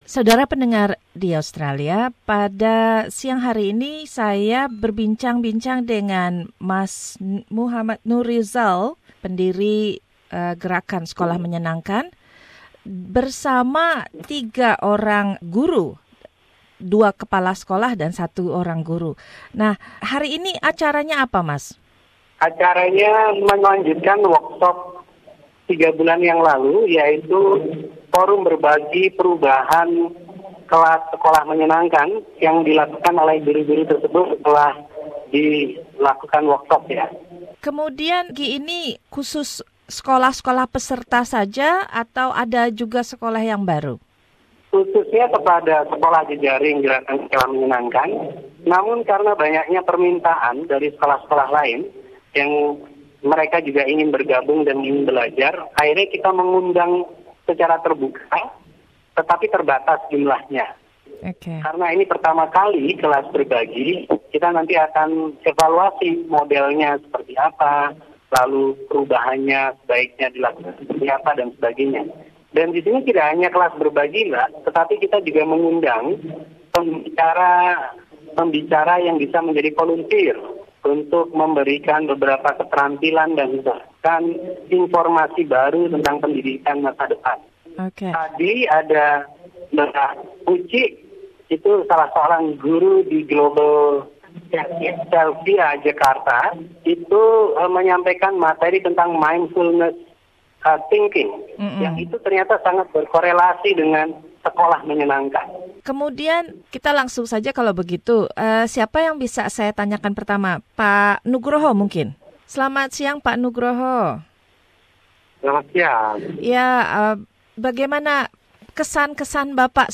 Dua orang kepala sekolah dan seorang guru dari sekolah-sekolah di Yogyakarta berbagi pengalamannya dalam wawancara ini.